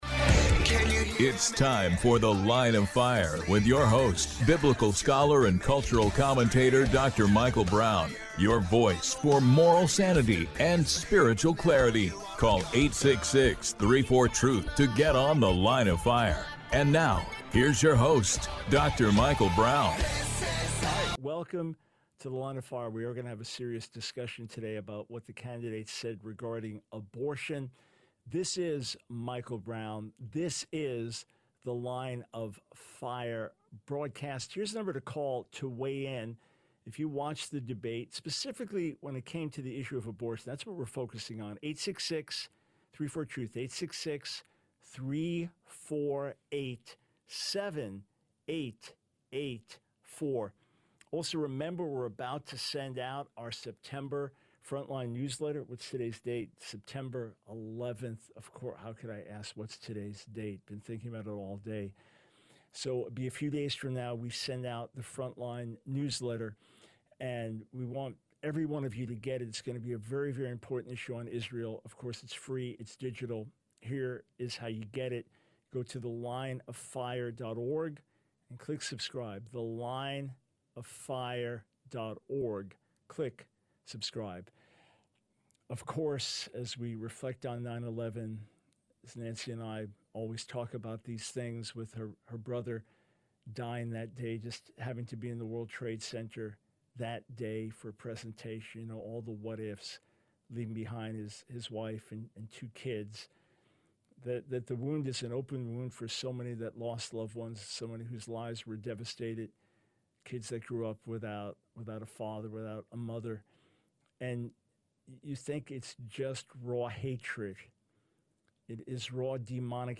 The Line of Fire Radio Broadcast for 09/11/24.